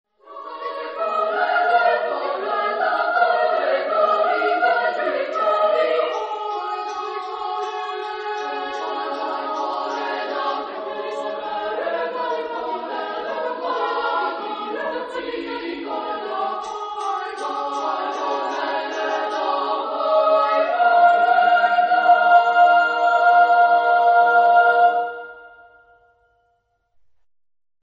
Género/Estilo/Forma: Canción de Navidad
Carácter de la pieza : alegre ; energico
Instrumentación: Percusión  (1 partes instrumentales)
Instrumentos: Pandereta (1)
Tonalidad : sol (centro tonal)